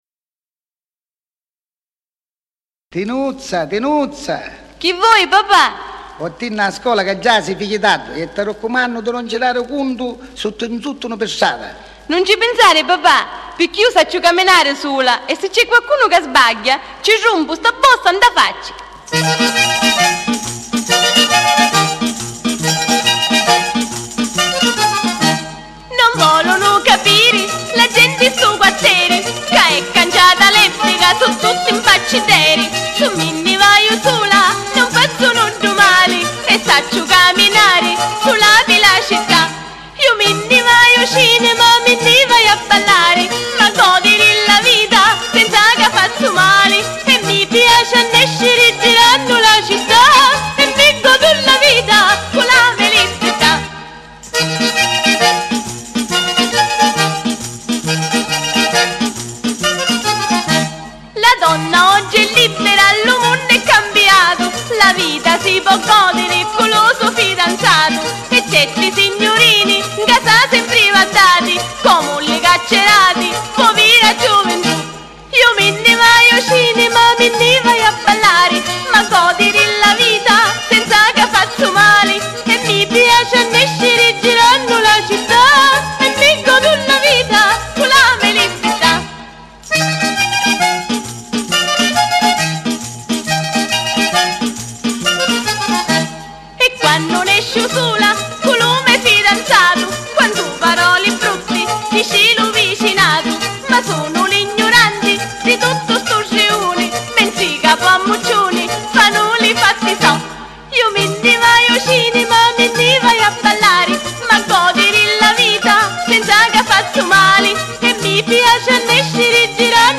COMPLESSO CARATTERISTICO SICILIANO
ZUFOLO
FISARMONICA